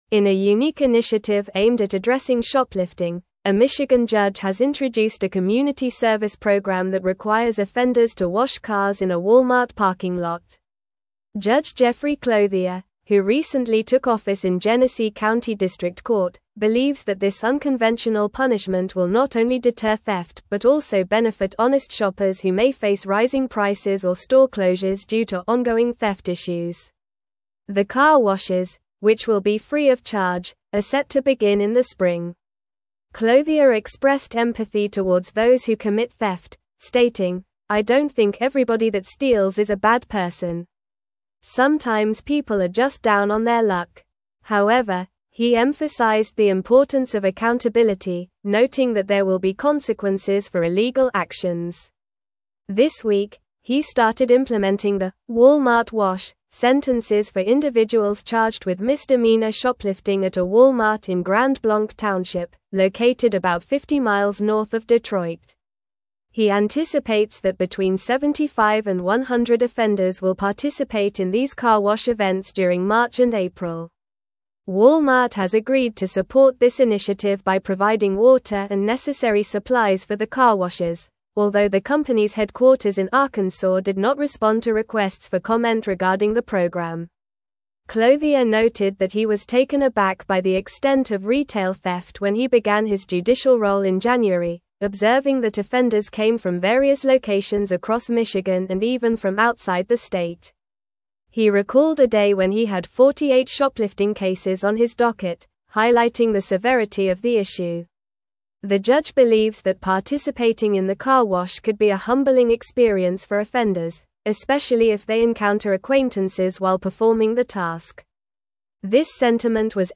Normal
English-Newsroom-Advanced-NORMAL-Reading-Michigan-Judge-Introduces-Unique-Community-Service-for-Shoplifters.mp3